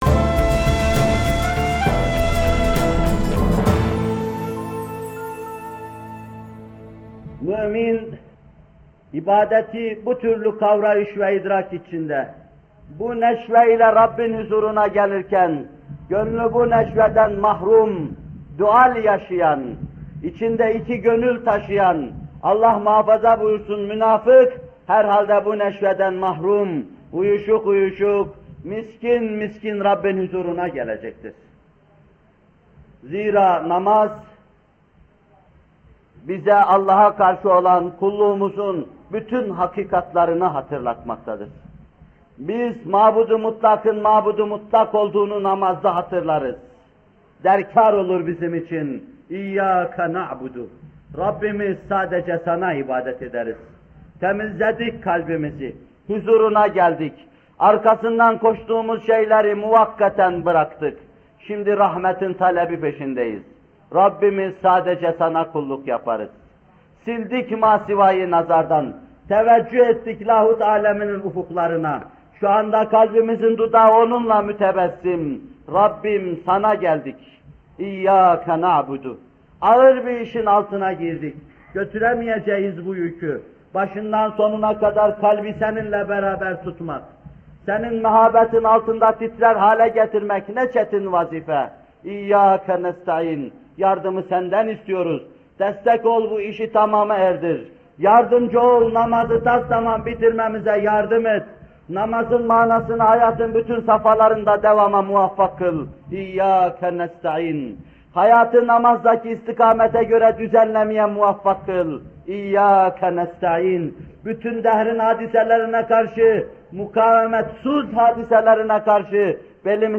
Bu bölüm Muhterem Fethullah Gülen Hocaefendi’nin 8 Eylül 1978 tarihinde Bornova/İZMİR’de vermiş olduğu “Namaz Vaazları 4” isimli vaazından alınmıştır.